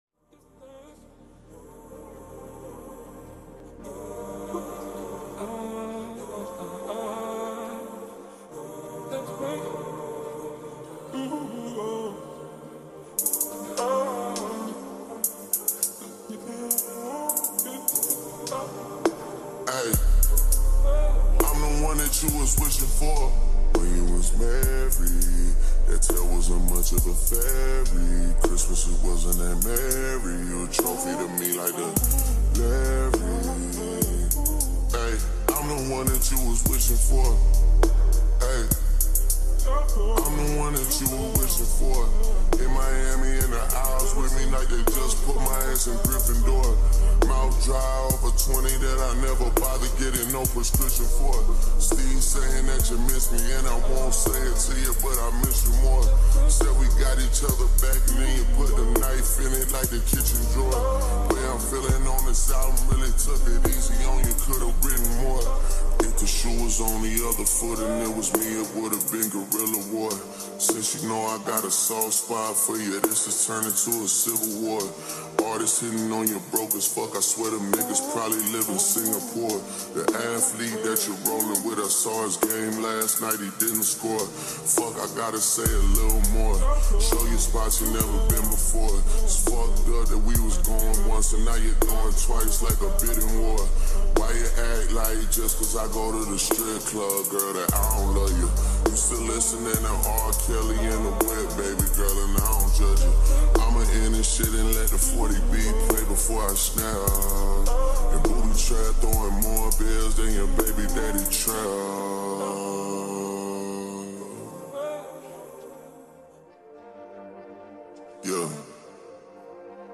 slow reverbed